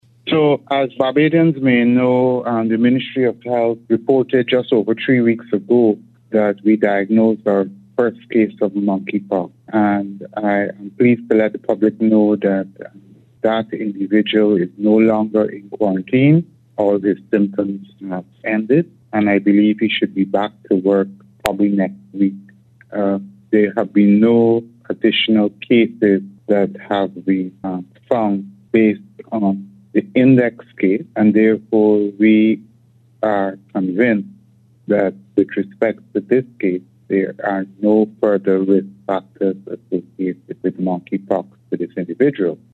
Dr. George gave Starcom Network news an update on the status of the virus in the country
Voice of: Chief Medical Officer Dr. Kenneth George